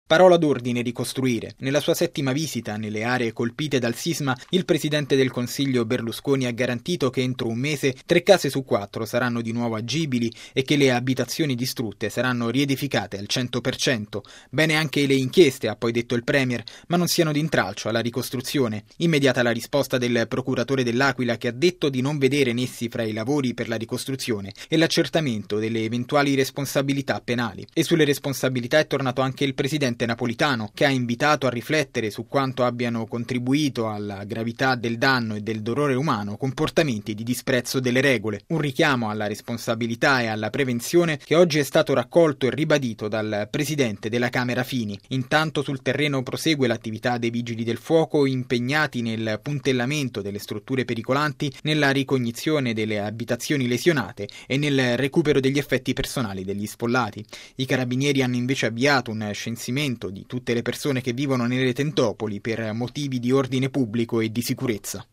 Intanto sul fronte politico tutti i riflettori sono puntati sulla ricostruzione e le indagini sui crolli. Il servizio